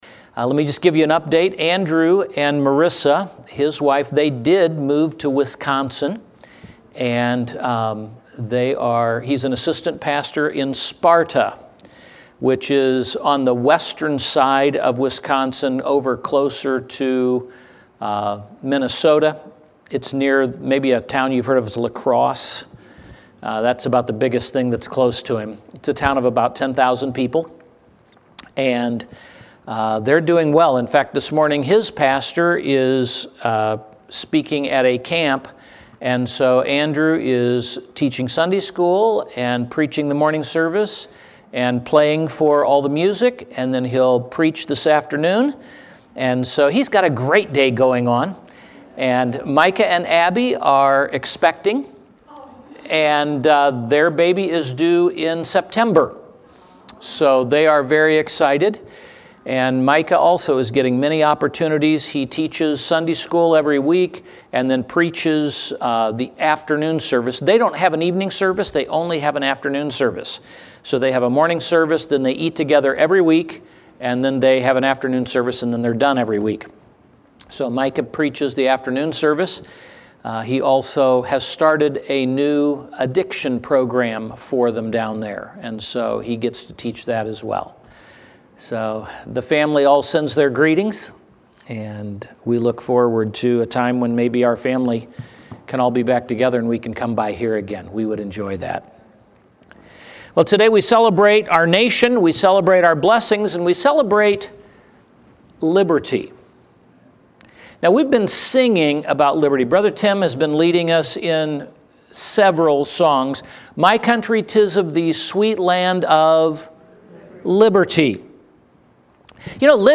Sunday Morning July 1, 2018 – Rock Solid Liberty